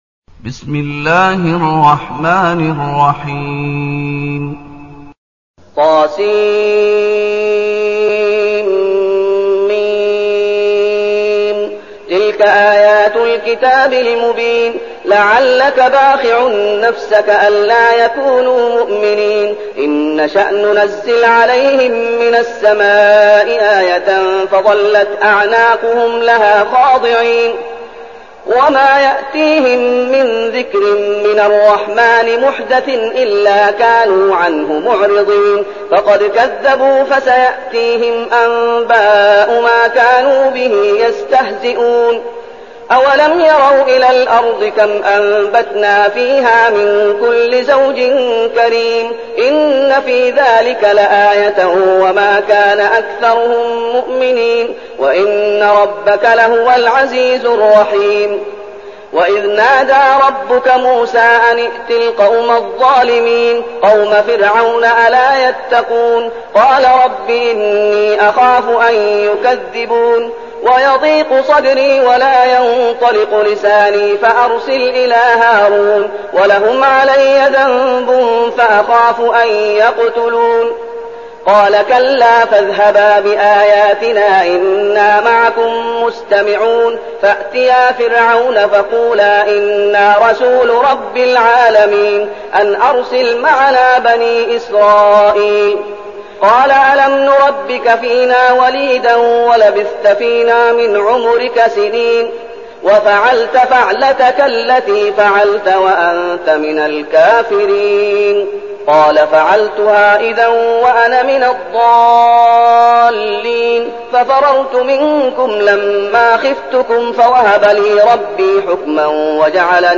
المكان: المسجد النبوي الشيخ: فضيلة الشيخ محمد أيوب فضيلة الشيخ محمد أيوب الشعراء